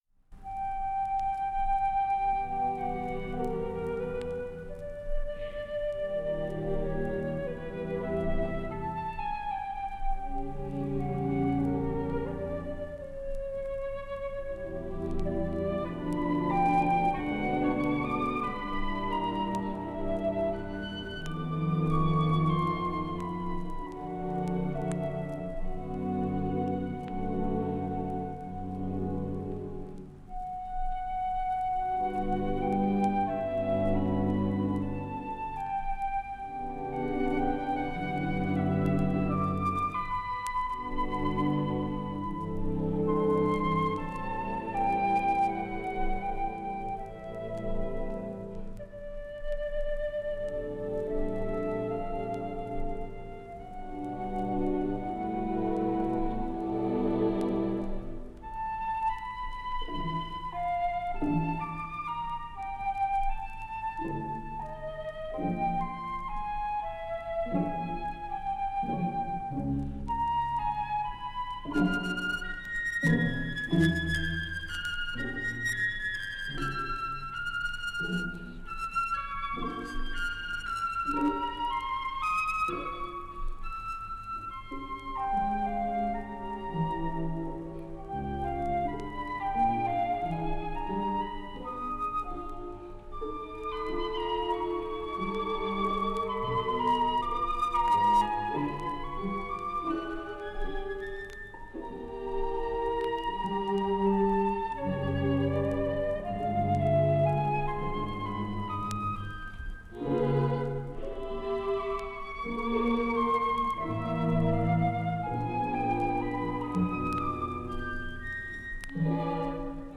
ritka kiváló felvételen.